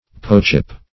poetship - definition of poetship - synonyms, pronunciation, spelling from Free Dictionary
Poetship \Po"et*ship\, n. The state or personality of a poet.